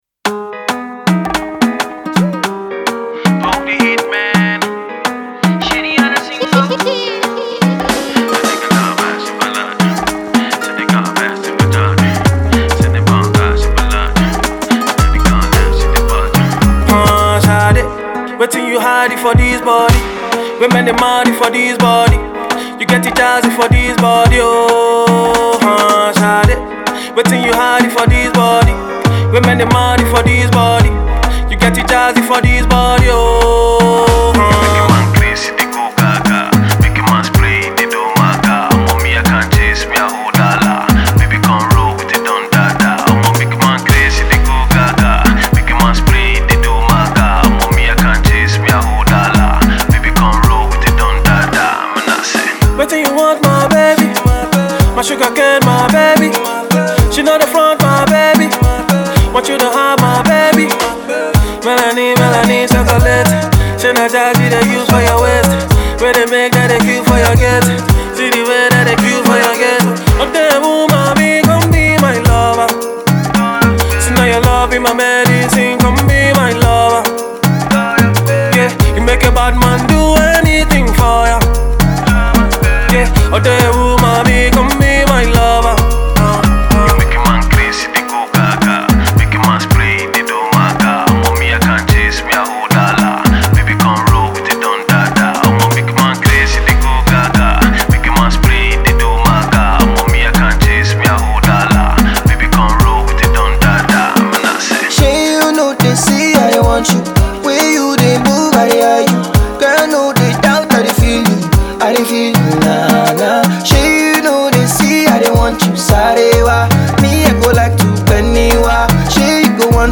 R&B/Afropop singer
a fusion of afrobeats and pop